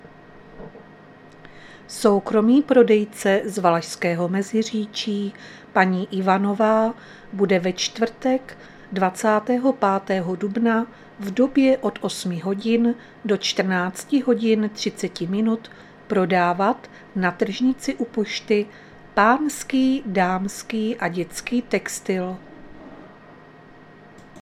Záznam hlášení místního rozhlasu 24.4.2024